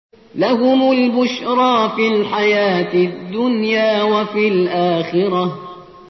دسته : پاپ